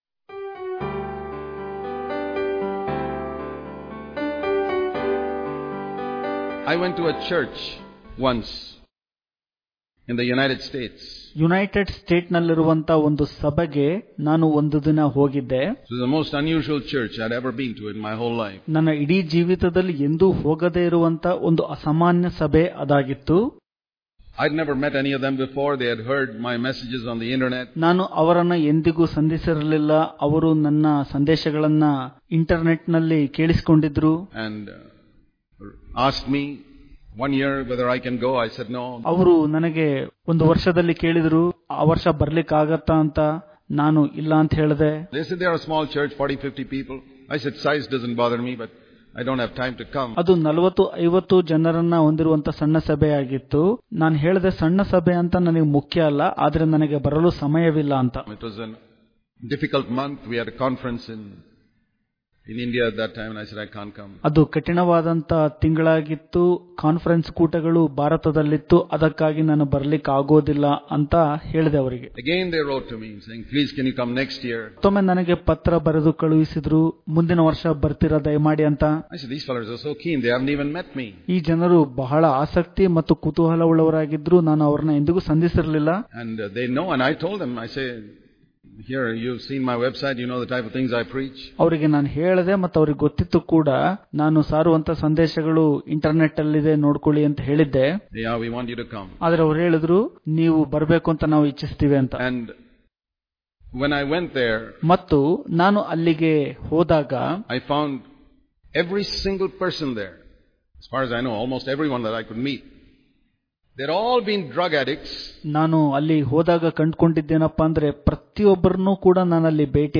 September 6 | Kannada Daily Devotion | God Does Not See As Man Sees Daily Devotions